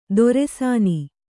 ♪ dore sāni